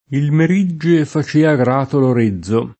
il mer&JJe fa©%a gr#to l or%zzo] (Ariosto); del Merigge ai mari [